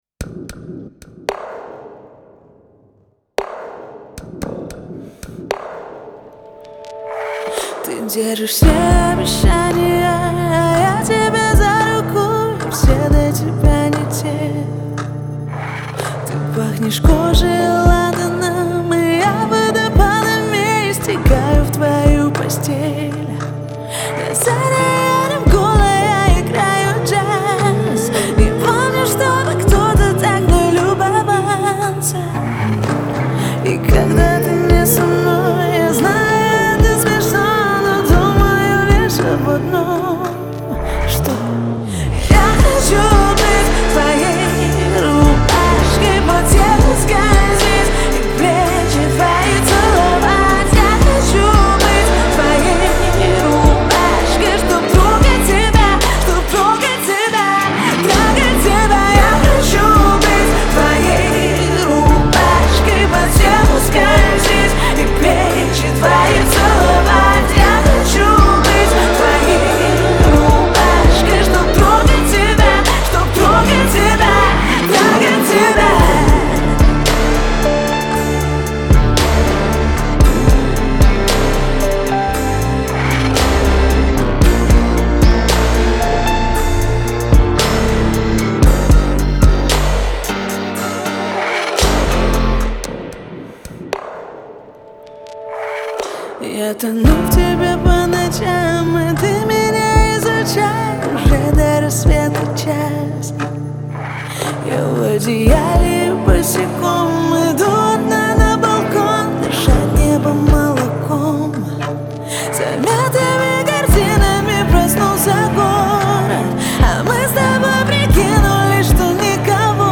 в жанре поп с элементами R&B